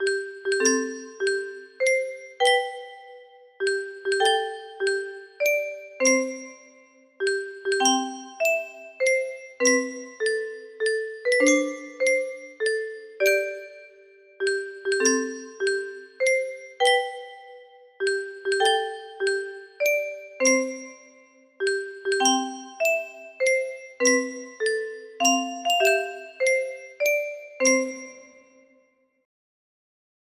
Clone of Happy Birthday To You music box melody
For 30, 20 and 15 notes music box